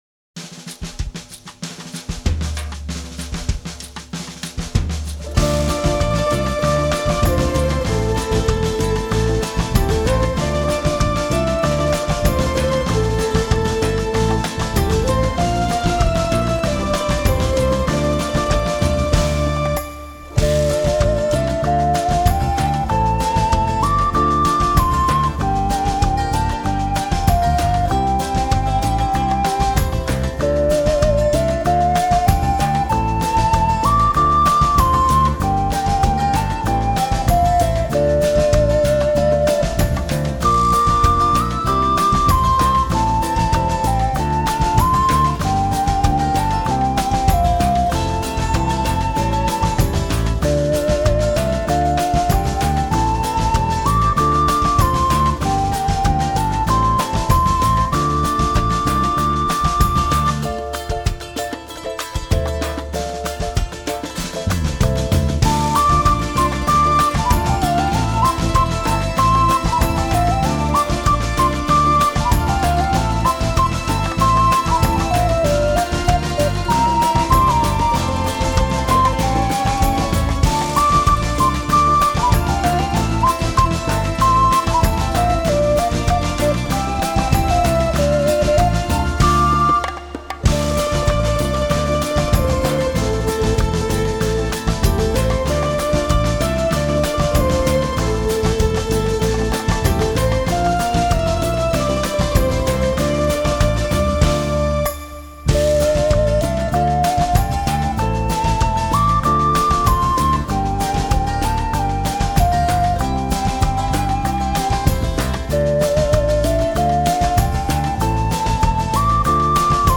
Genre: Newage.